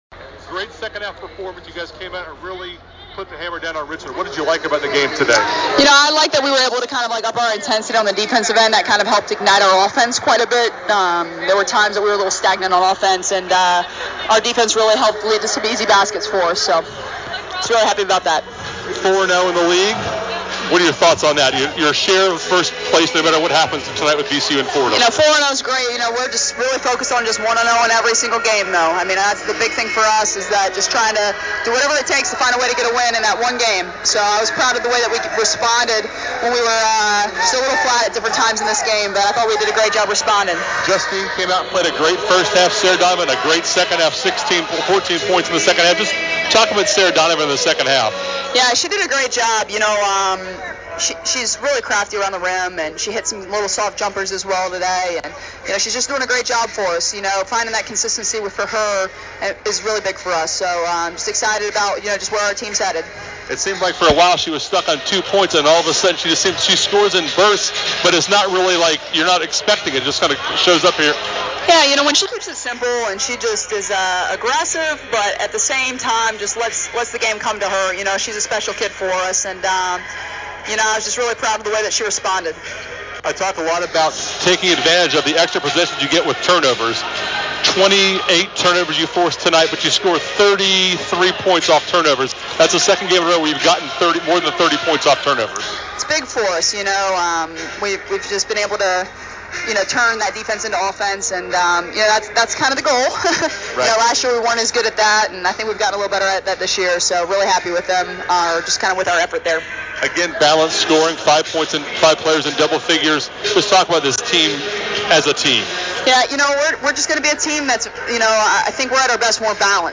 Postgame Interview
WBB Richmond Post Game.mp3